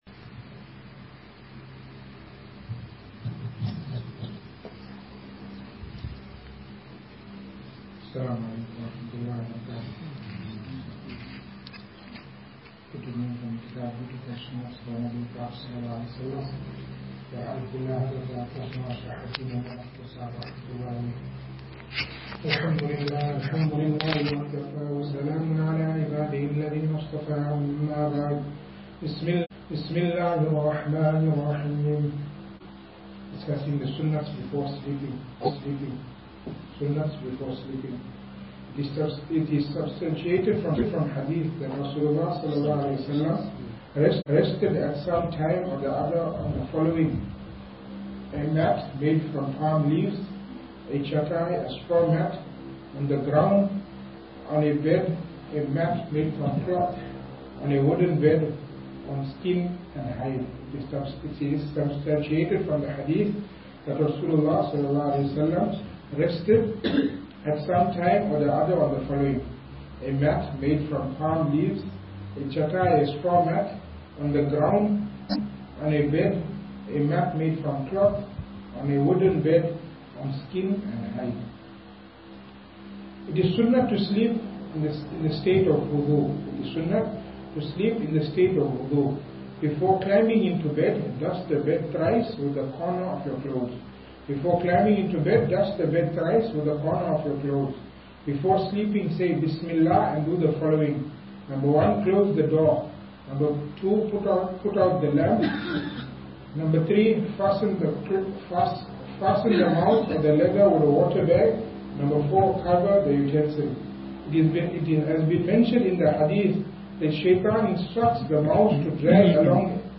Service Type: Majlis